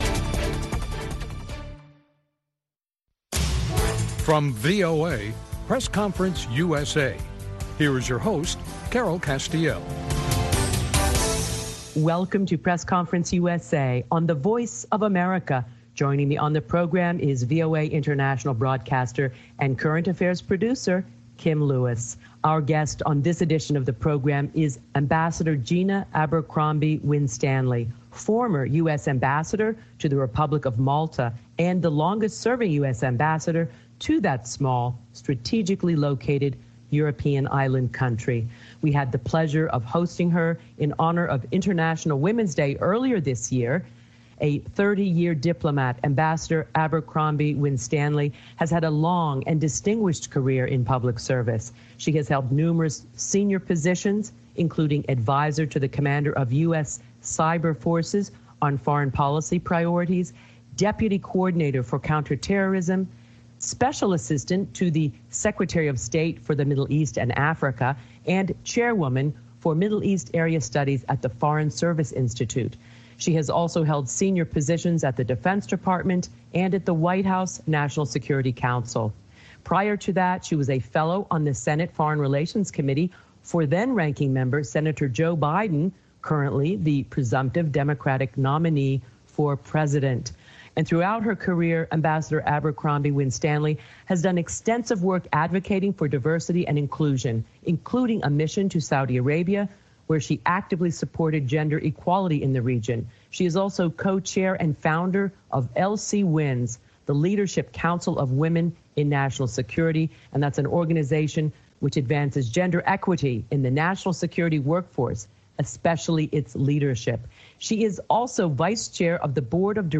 sit down with former US Ambassador to Malta, Gina Abercrombie-Winstanley, who discusses her views on race relations in America, her perspective on former Vice President Joe Biden, for whom she once worked, and several other critical issues related to election 2020 including cybersecurity, election security and mail-in ballots.